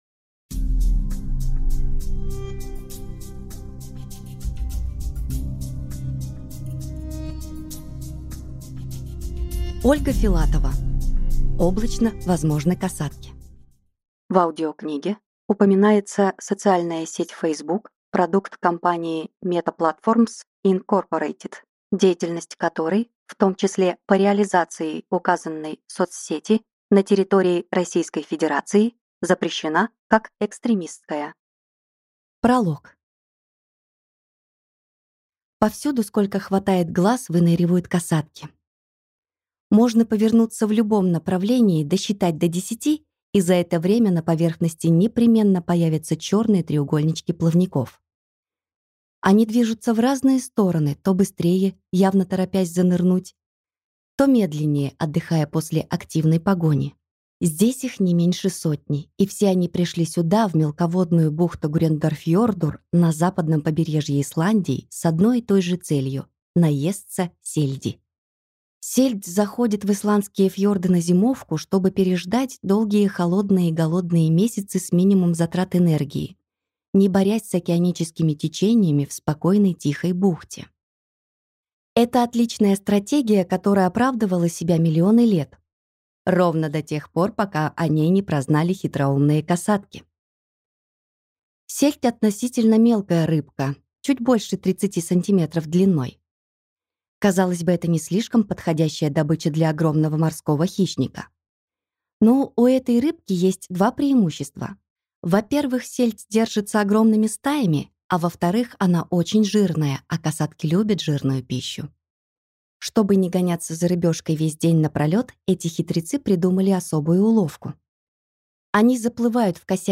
Аудиокнига Облачно, возможны косатки | Библиотека аудиокниг